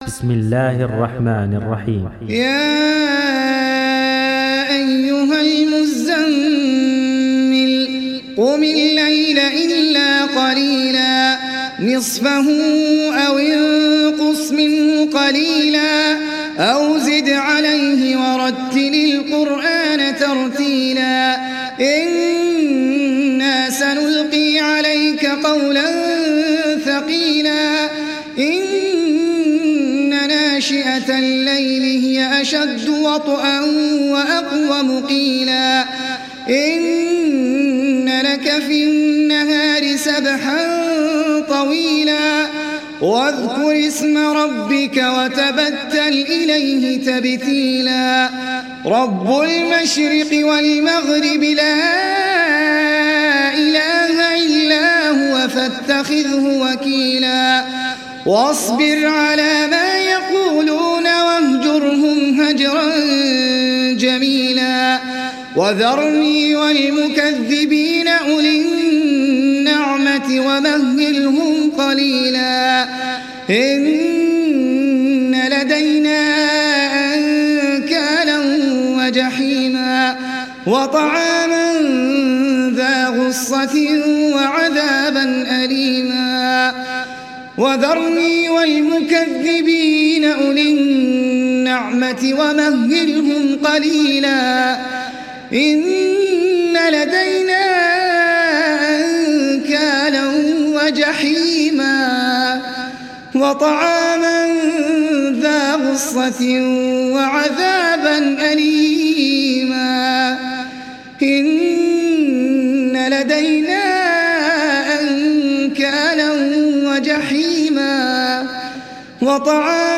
دانلود سوره المزمل mp3 أحمد العجمي (روایت حفص)